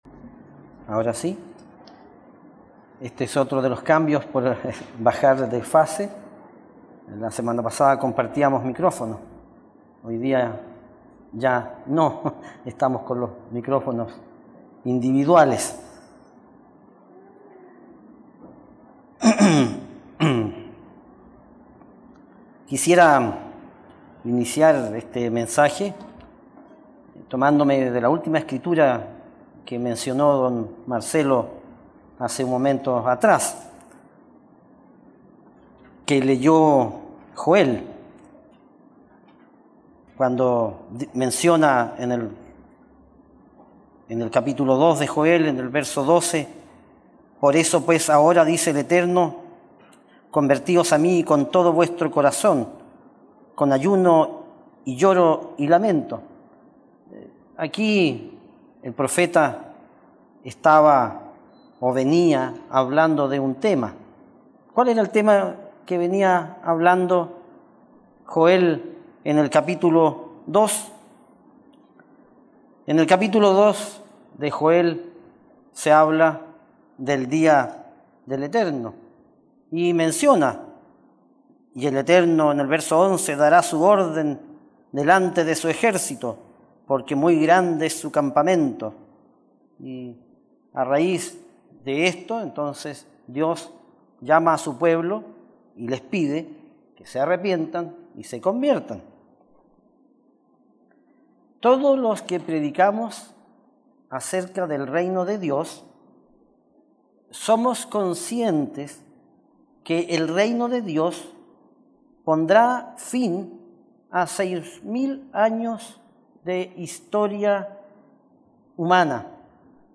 Mensaje entregado el 30 de octubre de 2021.